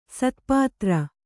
♪ satpātra